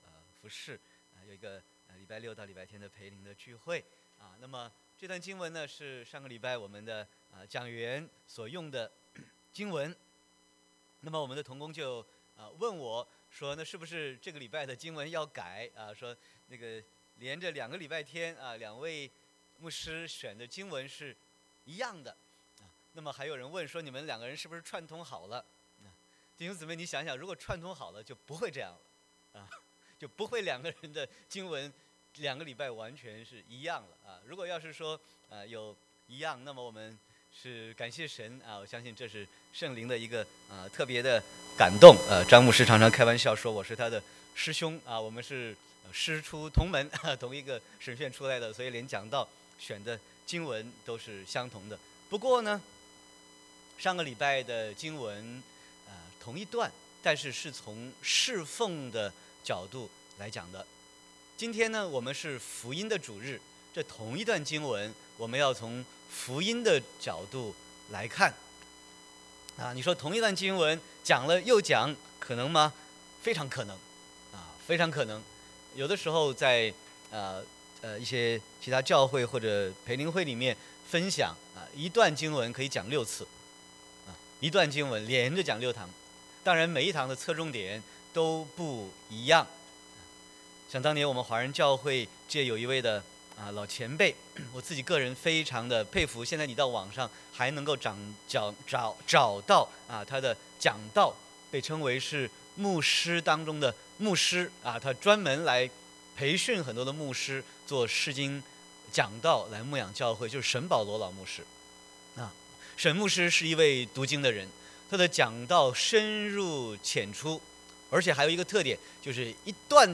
Sermon 03/17/2019